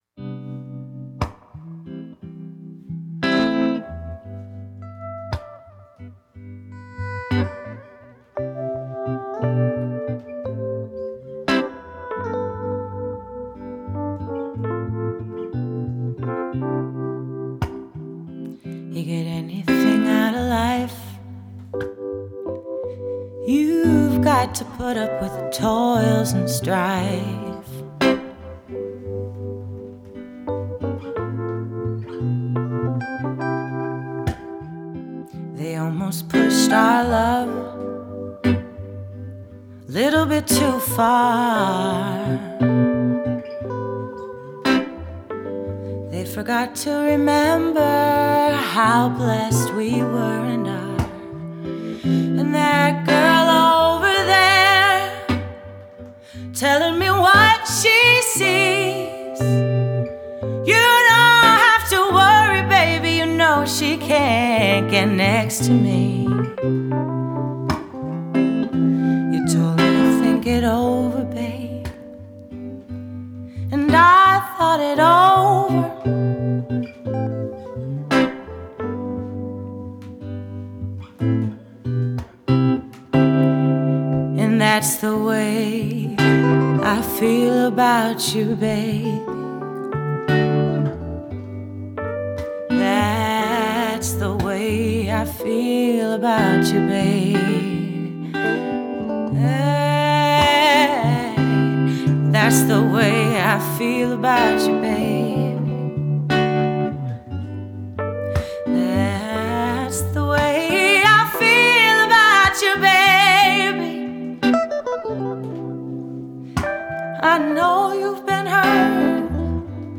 indie-soul
Known for her “honeyed swooping” vocals
bringing listeners along for an intimate and emotional ride.
guitar
Marcus King Bandon keys and organ.